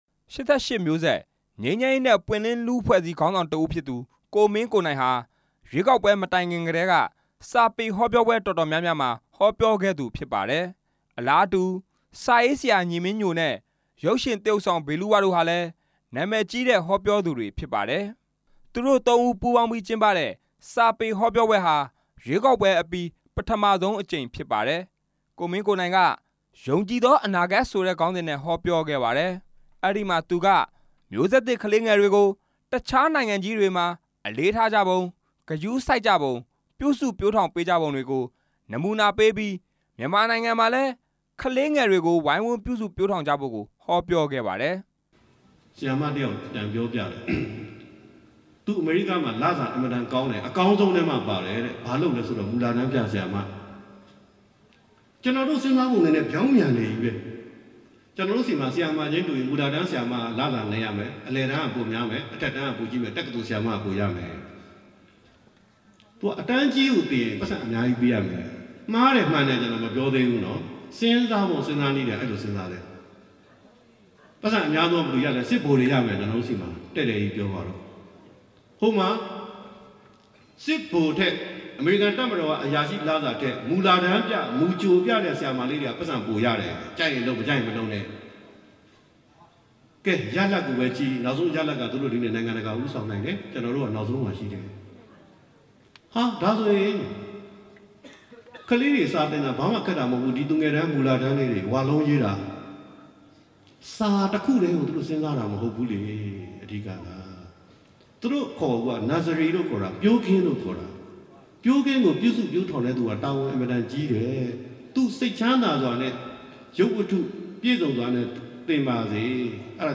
တာမွေမြို့နယ်က စာပေဟောပြောပွဲ